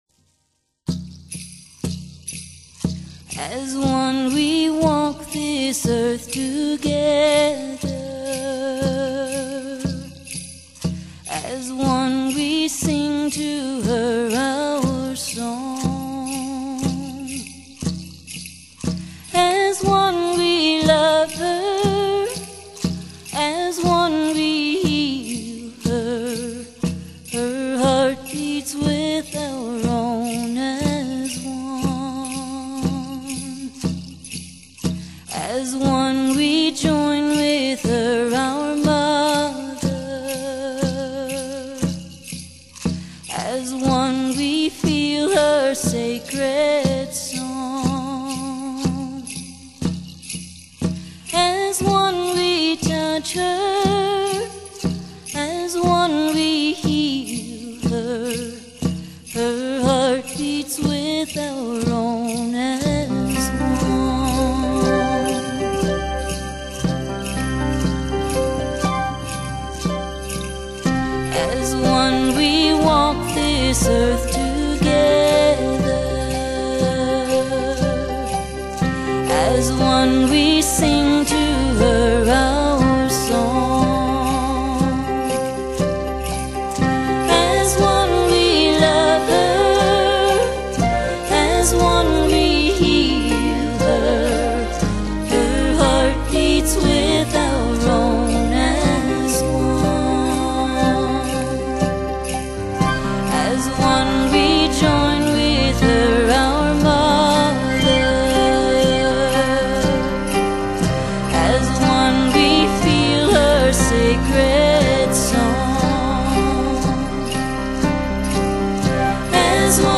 以清新的鼓樂配合多種樂器伴奏，模仿大自然之韻律，頌贊奇妙天地與人類之間的和諧.